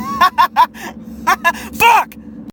child laughs